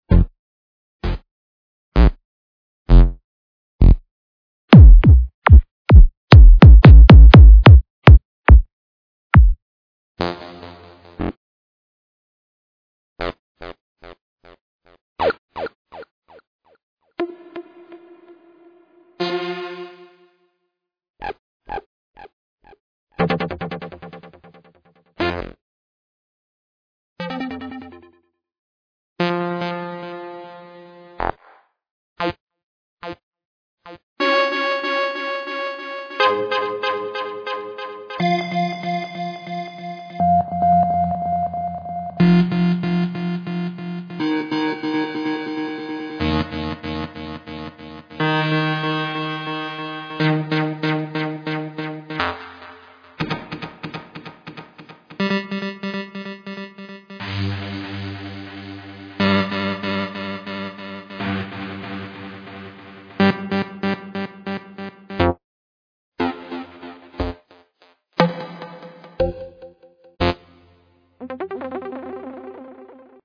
Trance music production
Including Kicks, Bass and Synths.